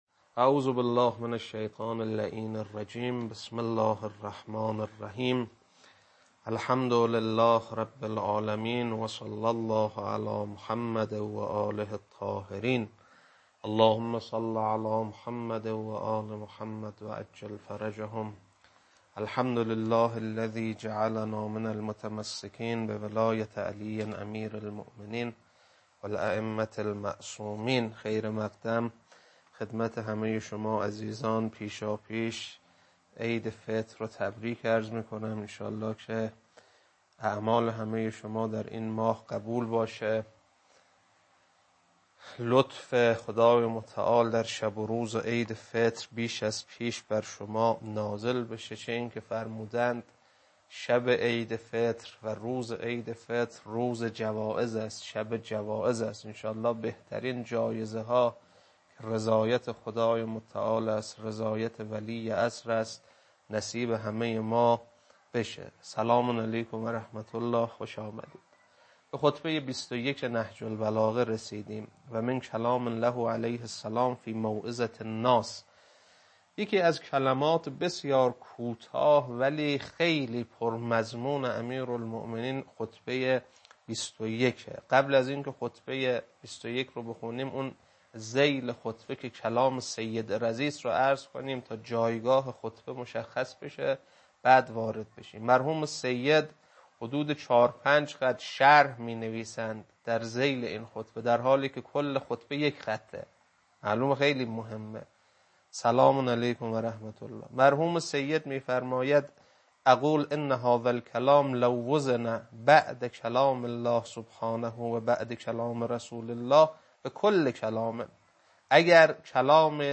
خطبه 21.mp3